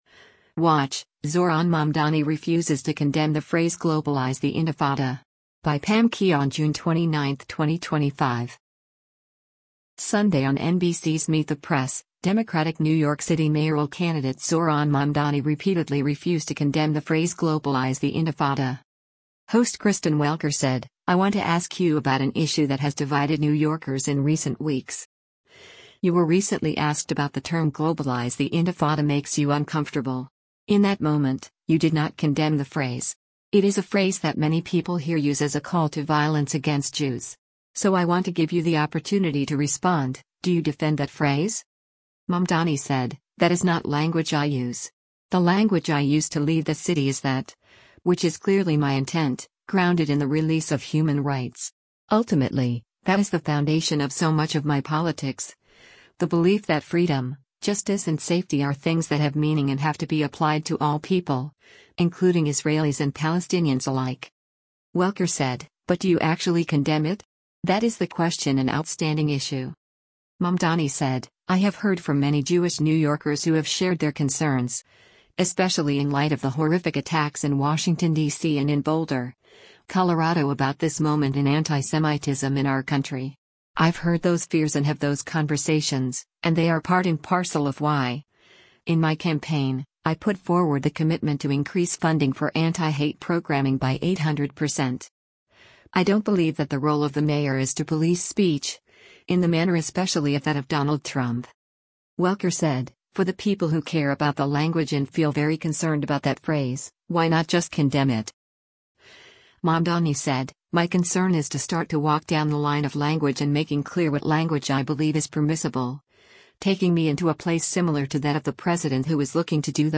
Sunday on NBC’s “Meet the Press,” Democratic New York City mayoral candidate Zohran Mamdani repeatedly refused to condemn the phrase “globalize the intifada.”